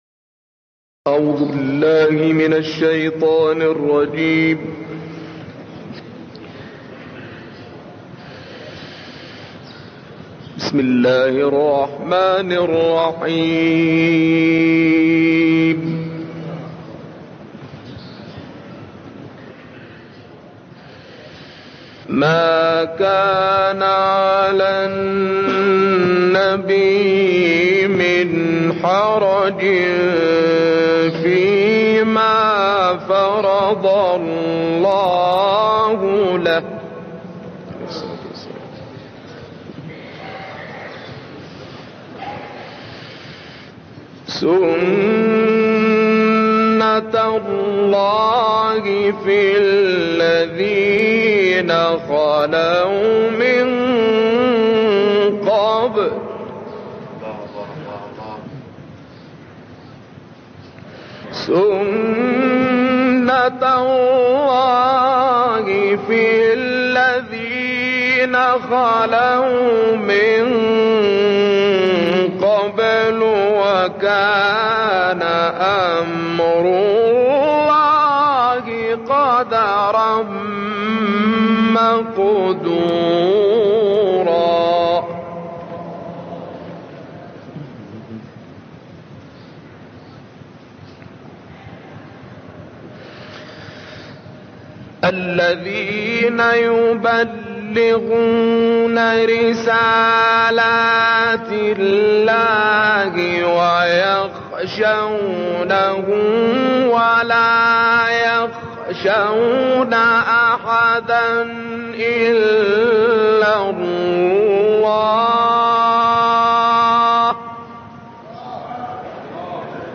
صوت | تلاوت «شعبان صیاد» از سوره احزاب
به مناسبت هفته وحدت و سالروز میلاد پیامبر مهربانی، تلاوت آیات ۳۸ تا ۴۸ سوره مبارکه احزاب را با صدای شعبان عبدالعزیز صیاد، قاری برجسته مصری می‌شنوید.